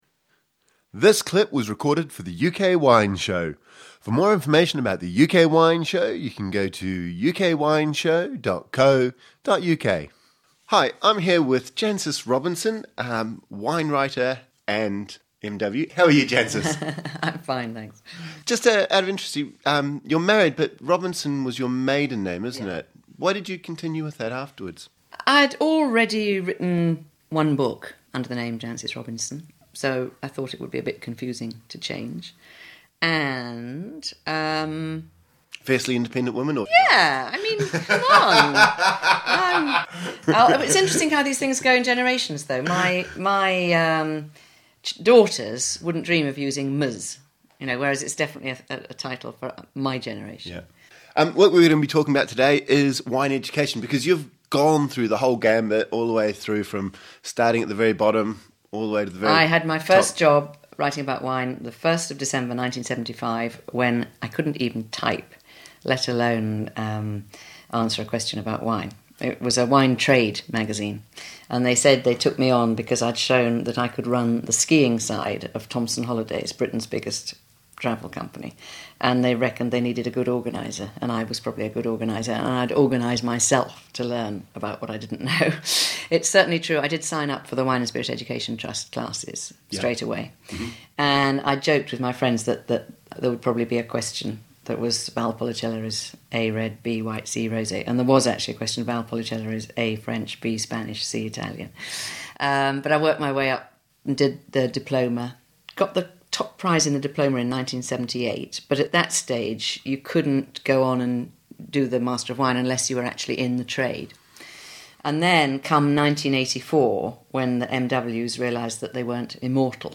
We ask her about her wine education and her advice for those wanting to further their own knowledge.